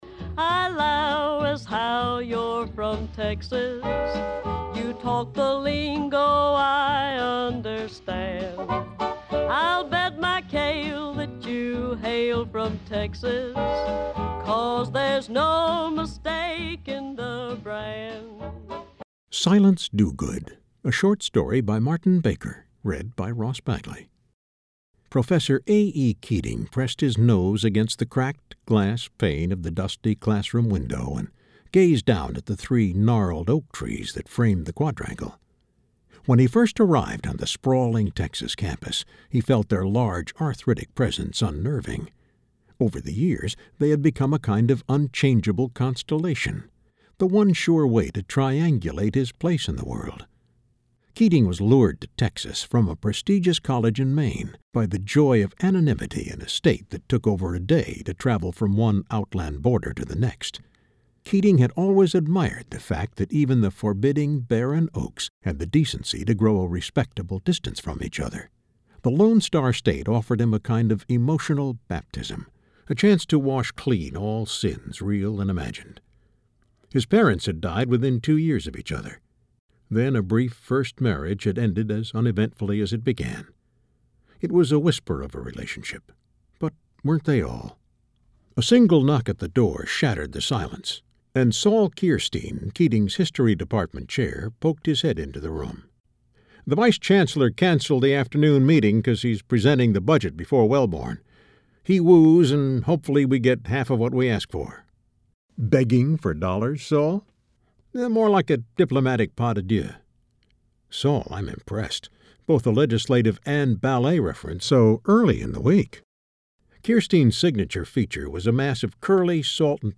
It was written for the eye but I wanted to see how it would translate to the ear.